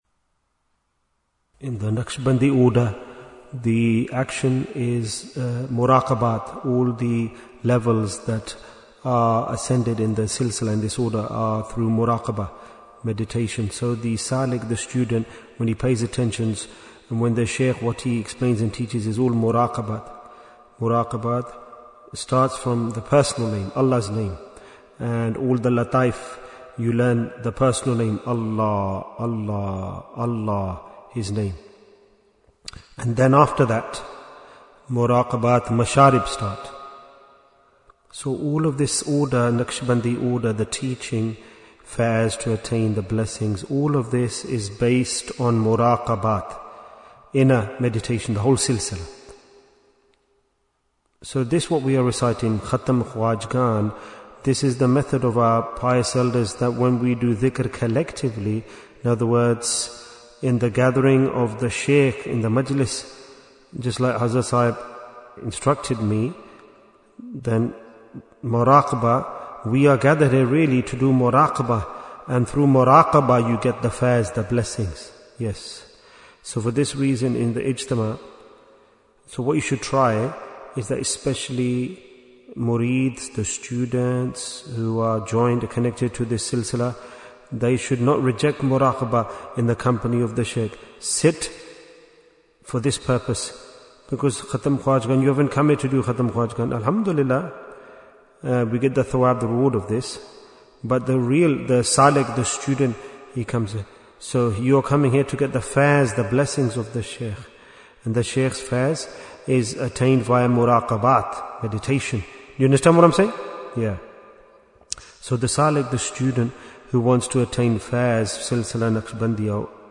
Why is Tazkiyyah Important? - Part 20 Bayan, 19 minutes7th March, 2026